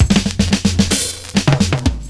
112FILLS03.wav